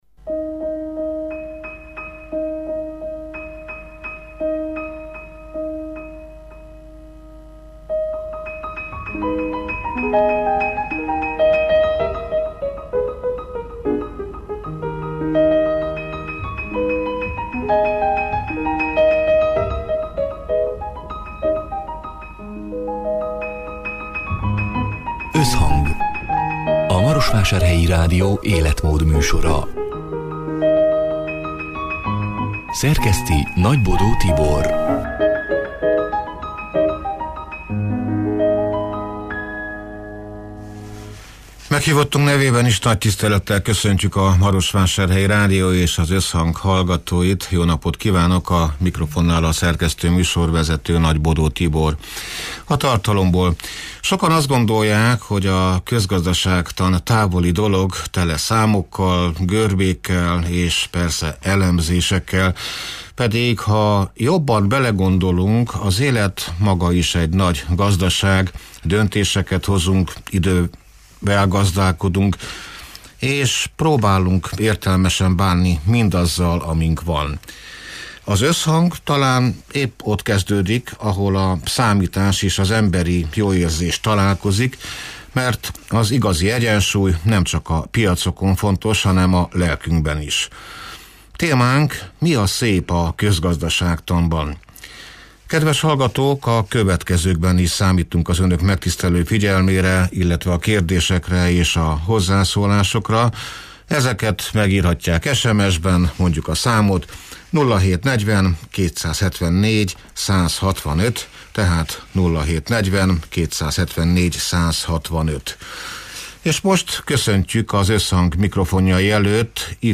A Marosvásárhelyi Rádió Összhang (elhangzott: 2025. április 23-án, szerdán délután hat órától élőben) c. műsorának hanganyaga: Sokan azt gondolják, hogy a közgazdaságtan távoli dolog, tele számokkal, görbékkel, elemzésekkel.